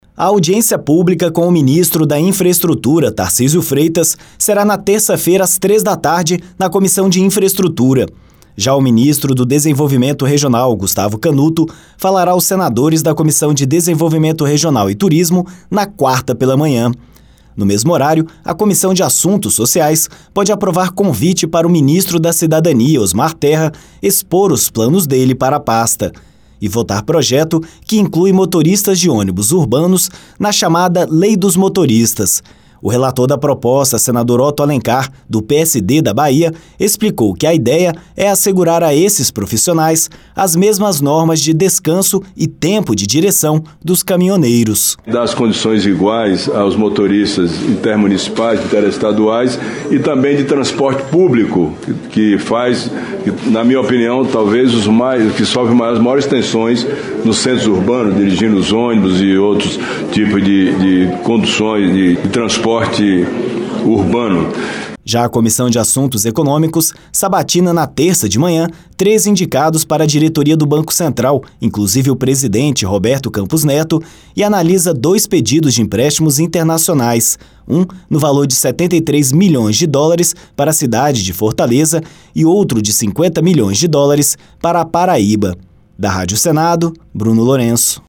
O senador Otto Alencar (PSD-BA) explicou que a ideia é assegurar a esses profissionais as mesmas normas de descanso e tempo de direção dos caminhoneiros.